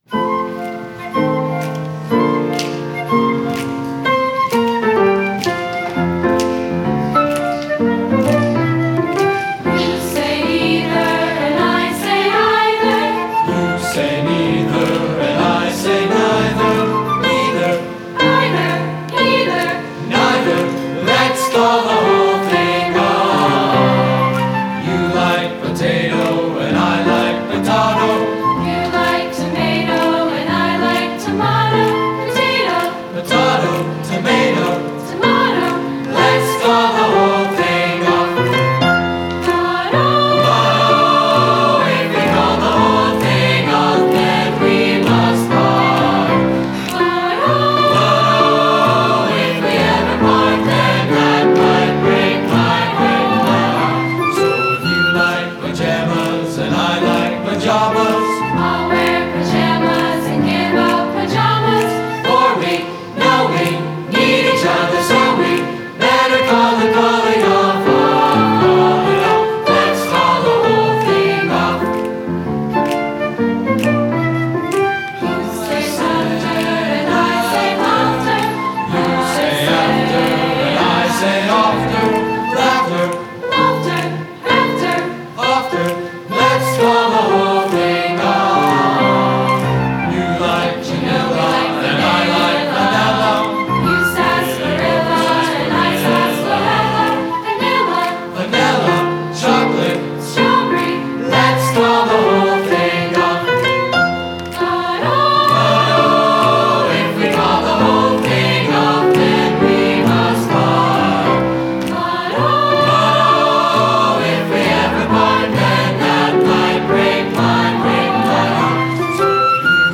Spring Music Festival
Brookline High School Roberts/Dubbs Auditorium, Brookline, MA
Concert Choir